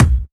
Tuned drums (C key) Free sound effects and audio clips
• Studio Bass Drum Single Shot C Key 645.wav
Royality free kick drum tuned to the C note. Loudest frequency: 716Hz
studio-bass-drum-single-shot-c-key-645-arD.wav